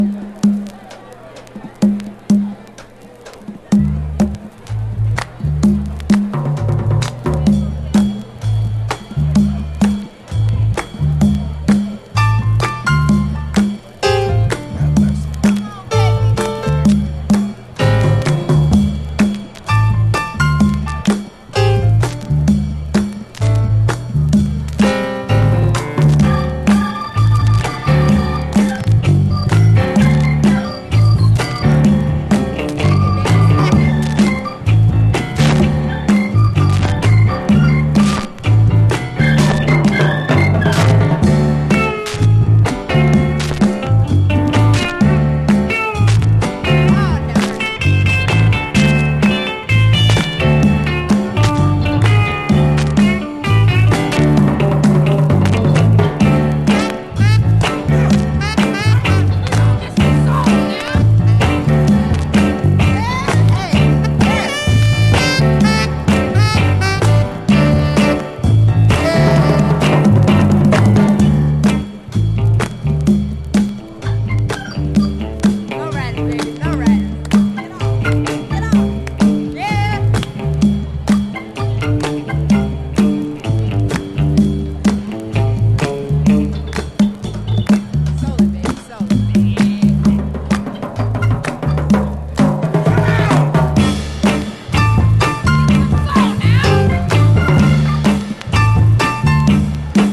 スレ・周回ノイズ箇所あるため試聴でご確認ください